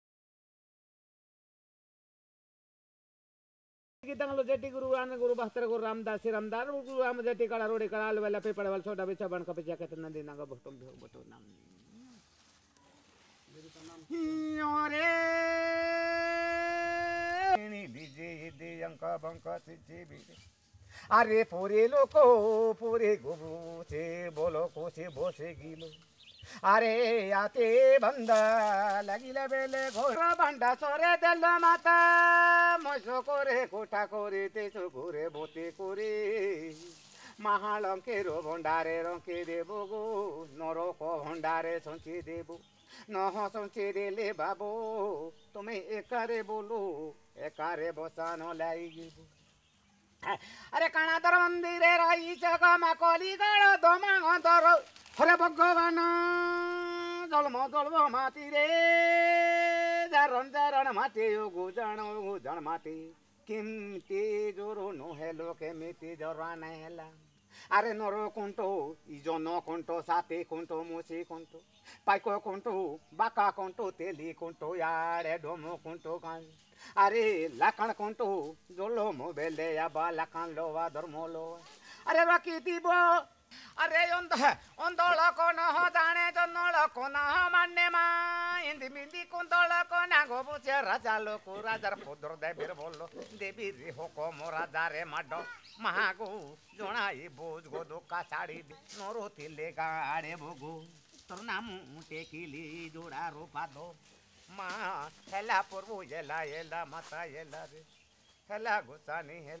Performance of a ritual